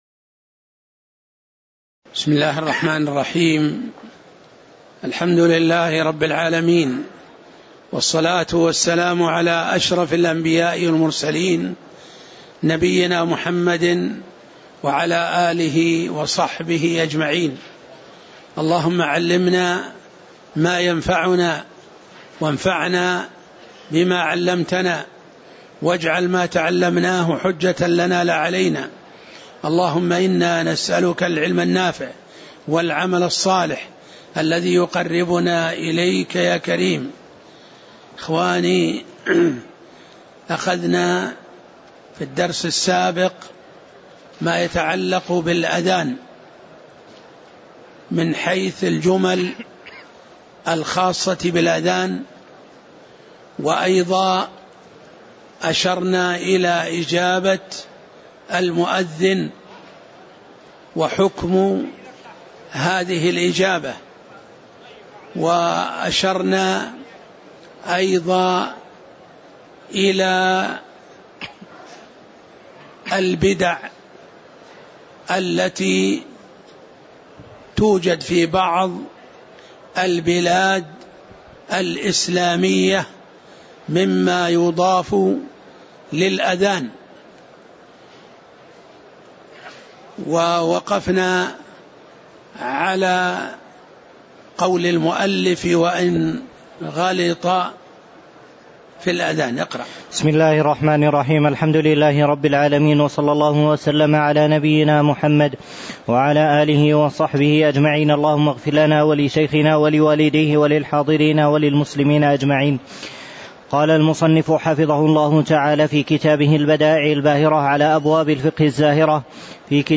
تاريخ النشر ١٤ صفر ١٤٣٨ هـ المكان: المسجد النبوي الشيخ